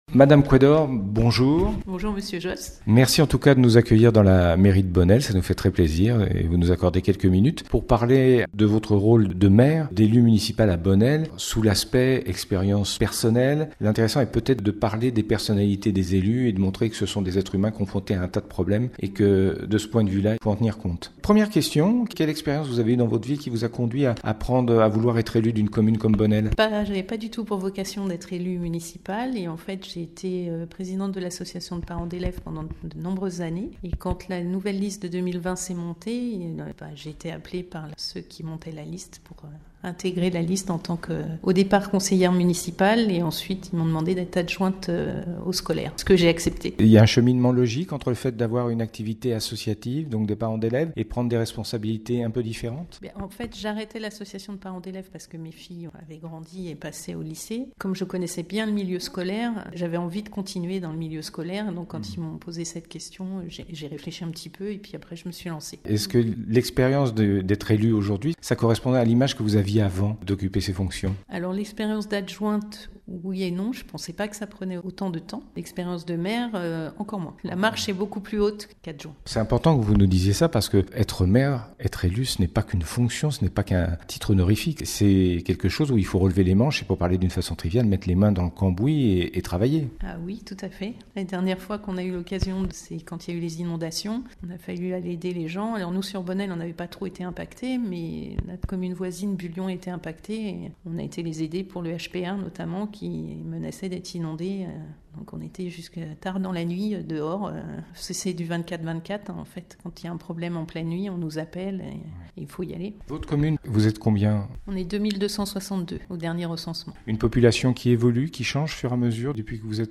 Interview de Madame Couëdor Maire de Bonnelles - Radio RVE